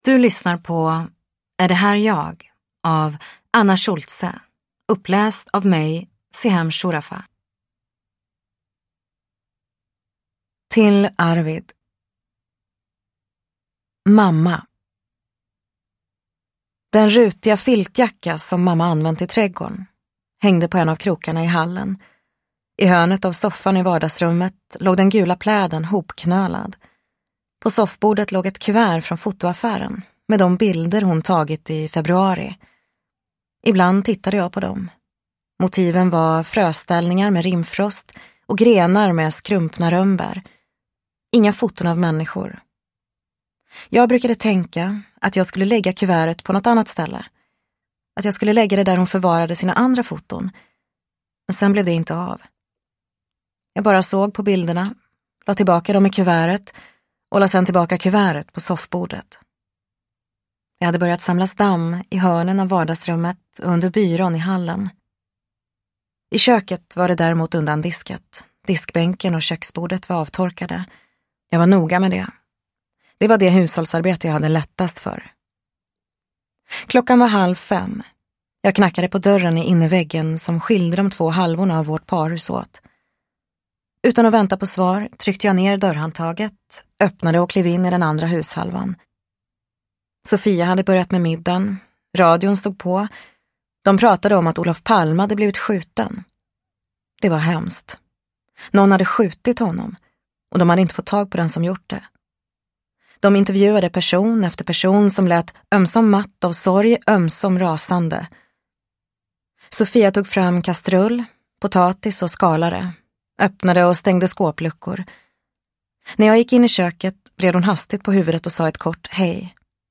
Är det här jag? – Ljudbok – Laddas ner